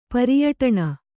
♪ pariyaṭaṇa